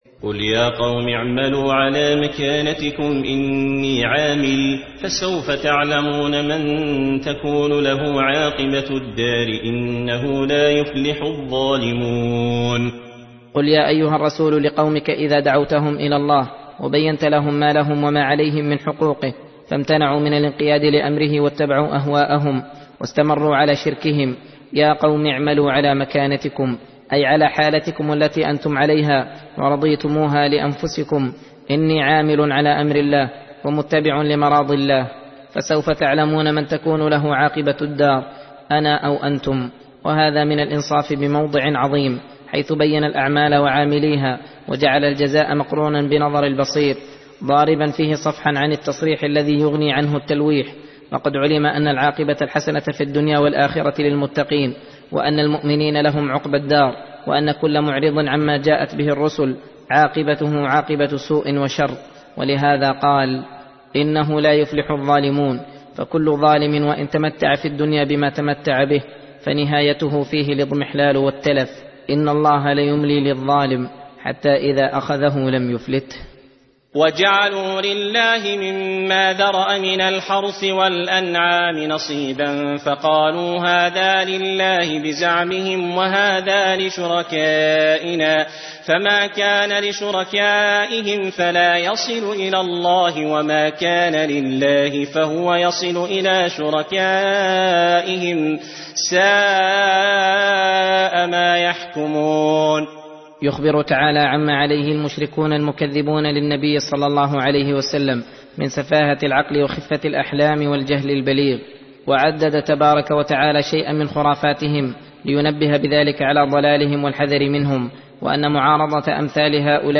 درس (33): تفسير سورة الأنعام : (135-144)